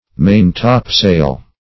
Meaning of main-topsail. main-topsail synonyms, pronunciation, spelling and more from Free Dictionary.